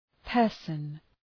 Προφορά
{‘pɜ:rsən}